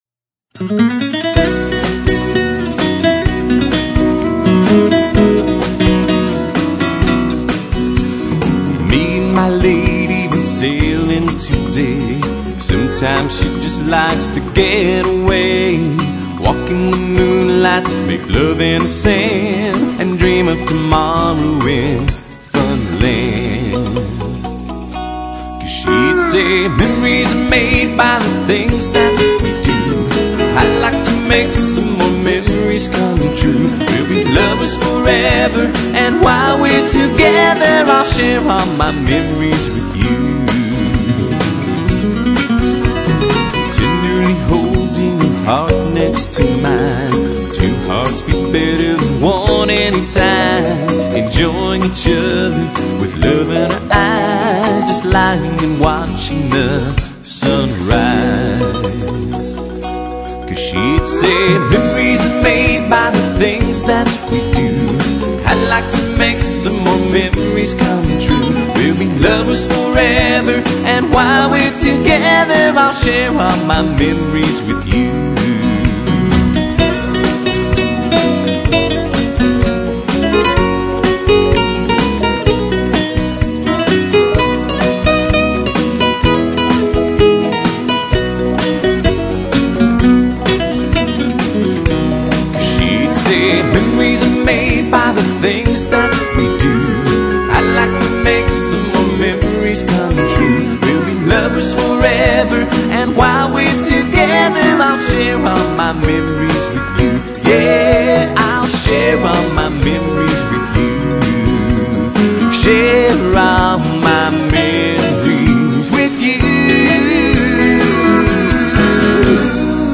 an original with a Spanish beat